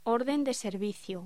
Locución: Orden de servicio